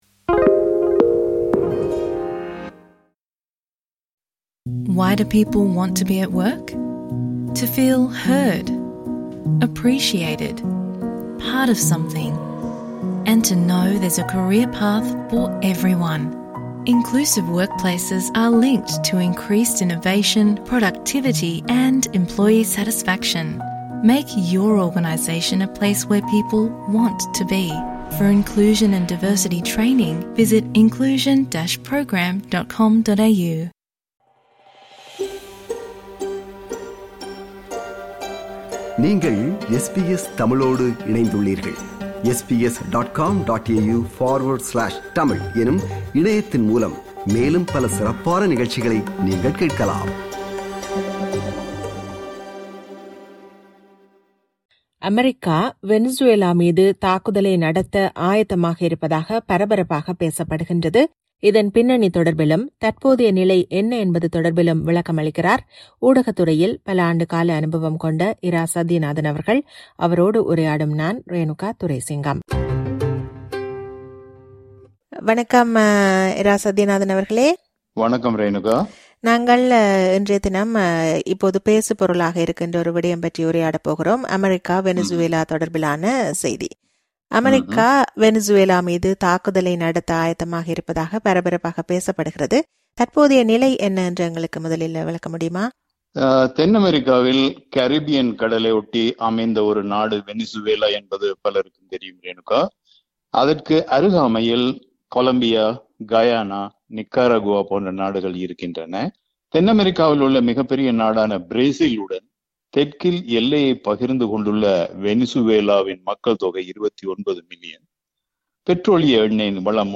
அமெரிக்கா, வெனிசுவேலா மீது தாக்குதலை நடத்த ஆயத்தமாக இருப்பதாக பரபரப்பாகப் பேசப்படுகிறது. இதன் பின்னணி தொடர்பிலும் தற்போதைய நிலை என்ன என்பது தொடர்பிலும் விளக்குகிறார் ஊடகத்துறையில் பல ஆண்டுகளாக பணியாற்றும் மூத்த ஒலிபரப்பாளர்